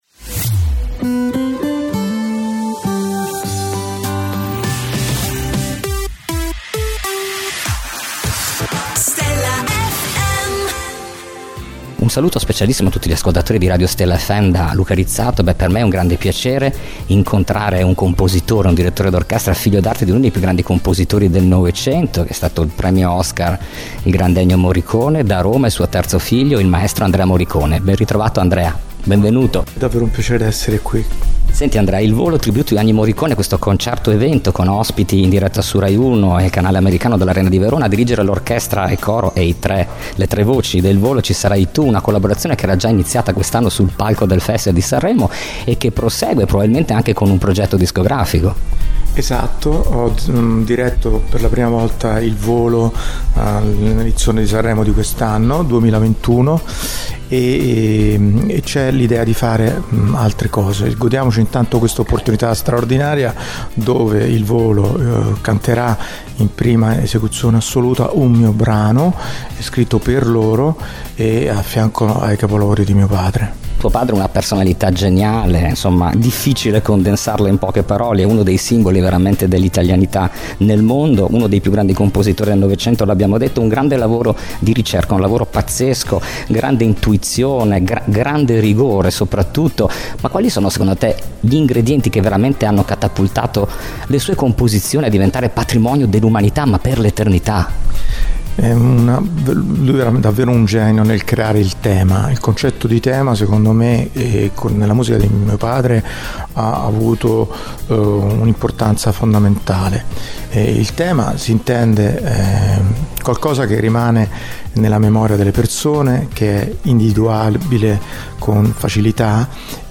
Intervista esclusiva dell’inviato per Stella FM a Andrea Morricone.